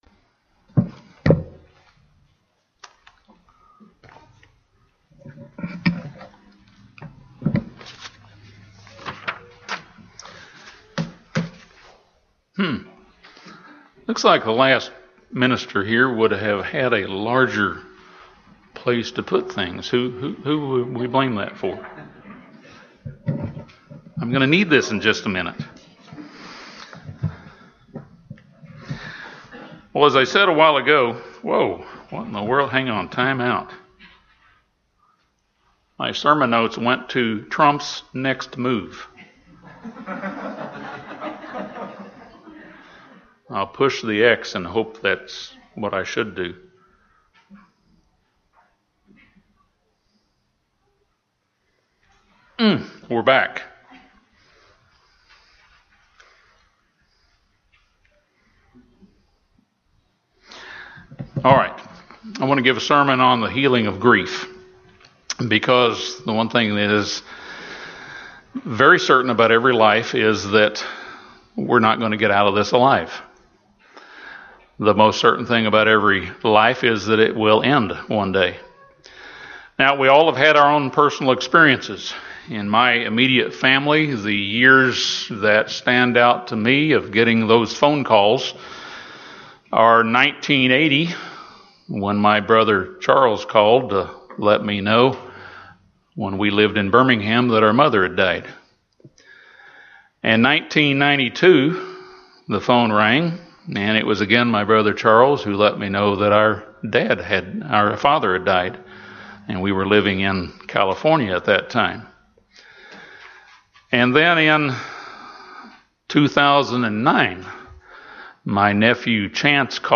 This sermon discusses common stages each of us travels as we struggle to heal from grief. Several points are offered that can help facilitate our healing from grief. Through the process we learn to be able to better help those who experience similar losses.